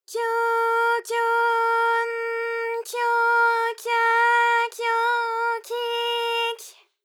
ALYS-DB-001-JPN - First Japanese UTAU vocal library of ALYS.
kyo_kyo_n_kyo_kya_kyo_kyi_ky.wav